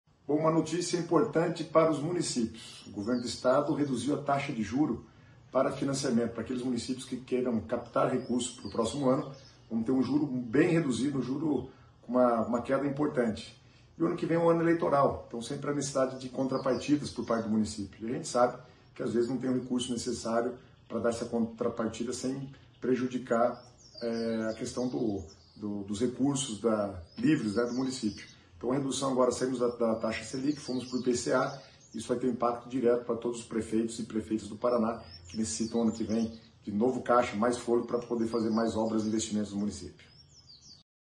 Sonora do secretário das Cidades, Guto Silva, sobre redução de taxas de financiamento para municípios